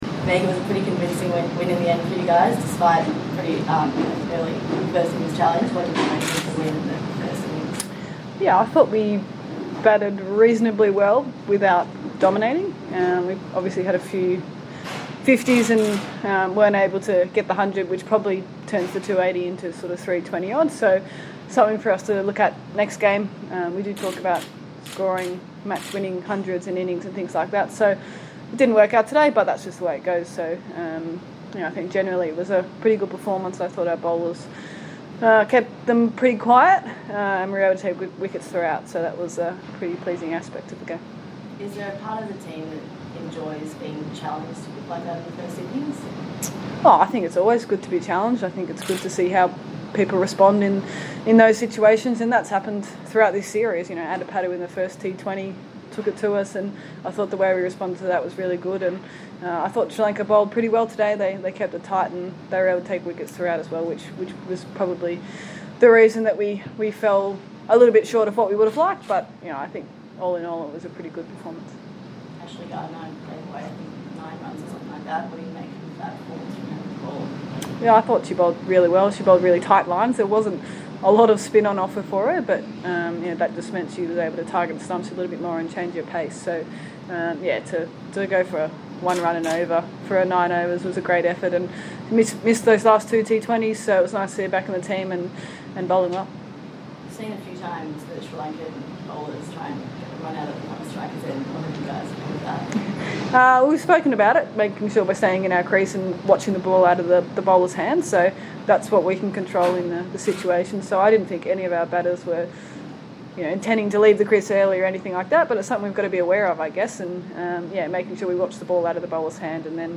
Meg Lanning post-match press conference interview